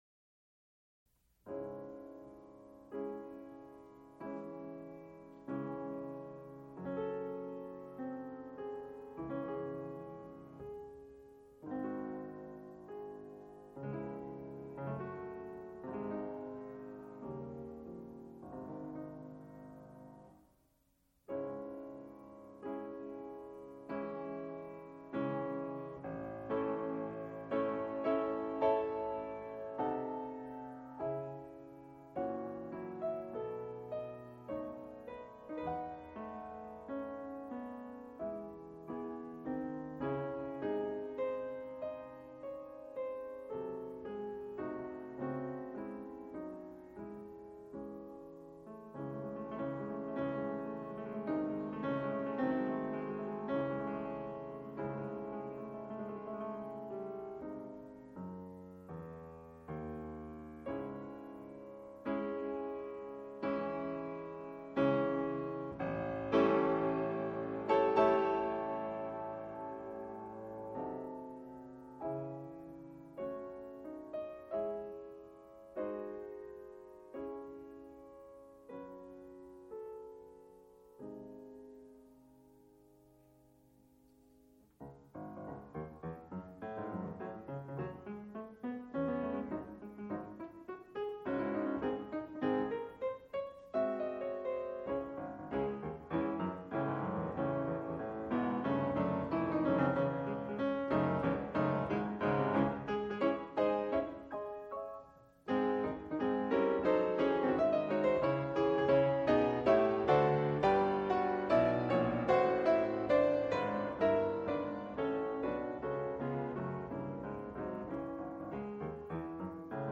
Student recital
piano., Recorded live February 23, 1978, Frick Fine Arts Auditorium, University of Pittsburgh.
Extent 2 audiotape reels : analog, half track, 7 1/2 ips ; 7 in.
Piano music Sonatas (Piano) Passacaglias (Piano)